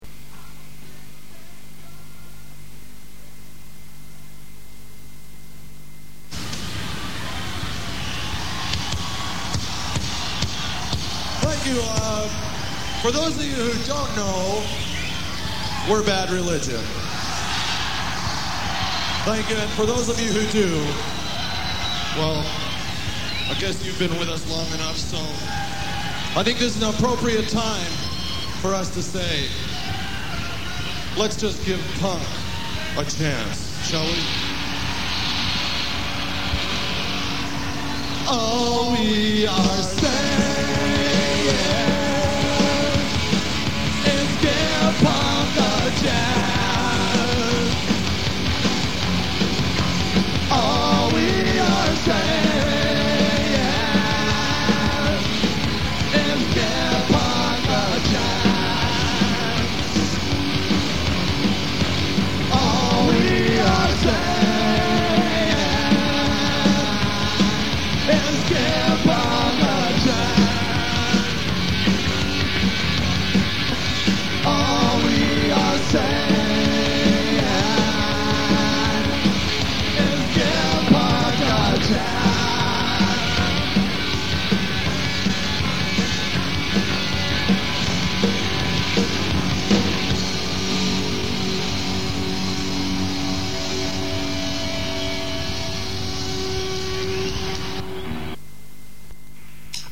Sang live